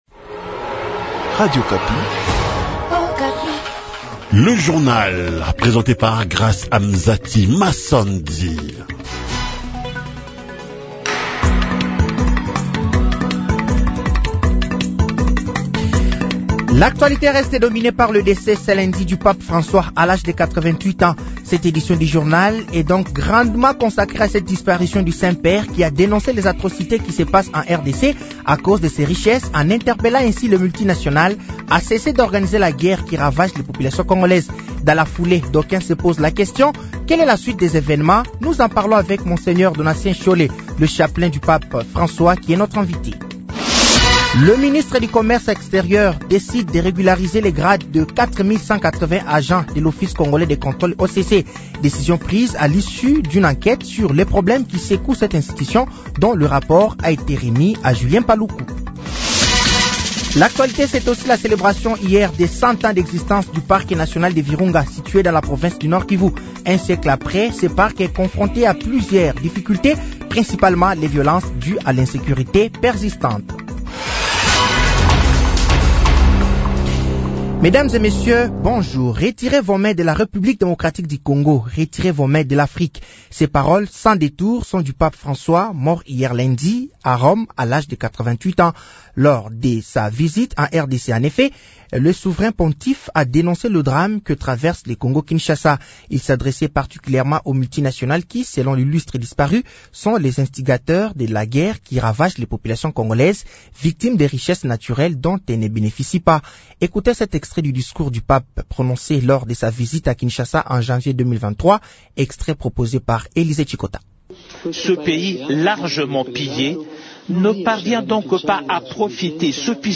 Journal français de 06h de ce mardi 22 avril 2025